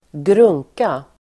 Ladda ner uttalet
grunka substantiv (vardagligt), thing [informal]Uttal: [²gr'ung:ka] Böjningar: grunkan, grunkorSynonymer: apparat, grej, mackapär, manick, mojäng, sakDefinition: sak, grej